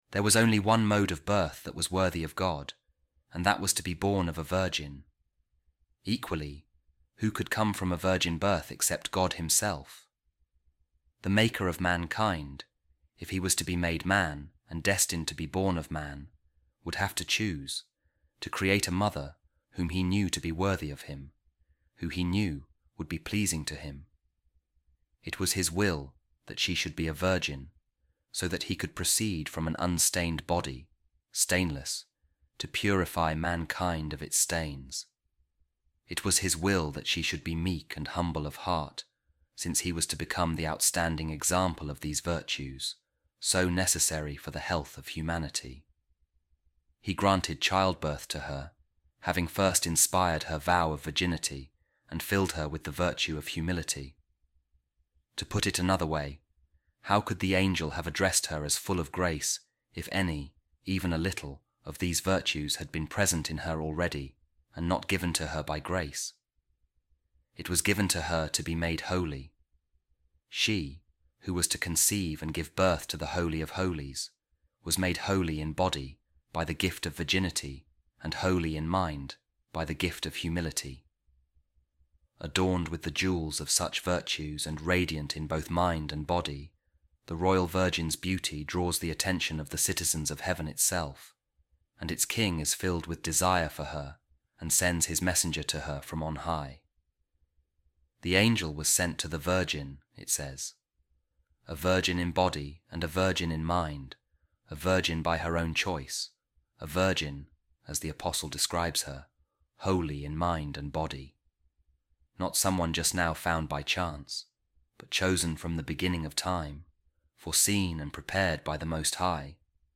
Office Of Readings | Week 20, Tuesday, Ordinary Time | A Reading From The Homilies Of St Bernard Of Clairvaux On The Glories Of The Virgin Mother | She Was Prepared By The Most High And Prefigured By The Patriarchs